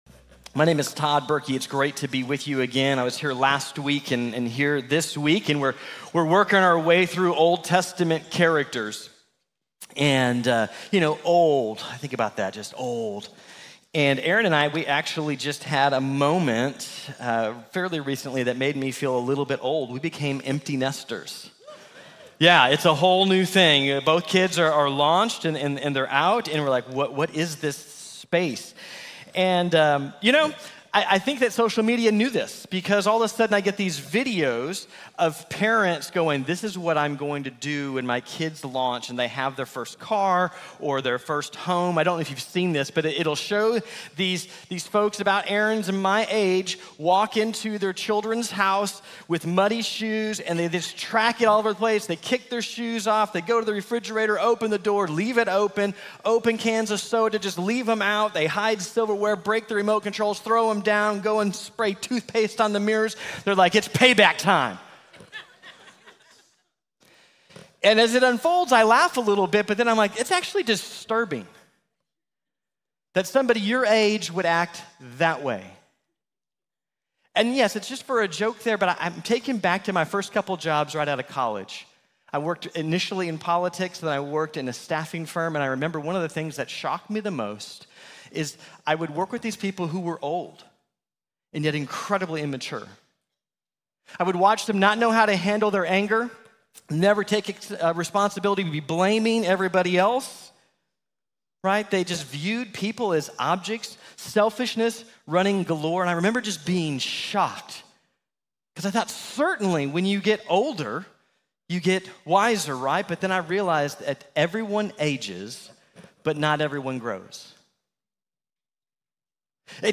Not Everyone Grows | Sermon | Grace Bible Church